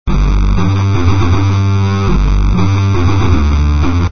Index of /Habbo_Archive_V2_Wendigo/HabboStuff/Cokestudios Private Server/Cokemusic/src/Mixer Library/Dance Sounds
danceOne_bass00.mp3